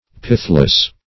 Search Result for " pithless" : The Collaborative International Dictionary of English v.0.48: Pithless \Pith"less\, a. Destitute of pith, or of strength; feeble.